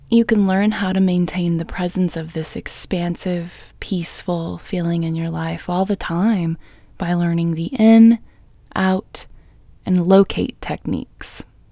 QUIETNESS Female English 21
Quietness-Female-21-1.mp3